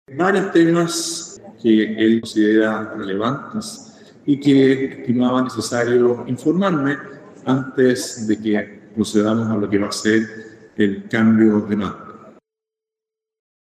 Tras el encuentro y durante un breve punto de prensa — donde no respondió preguntas a los medios — el presidente electo afirmó que la solicitud de reunirse había surgido desde el propio Presidente Boric, quien le solicitó este encuentro para tratar algunos temas importantes.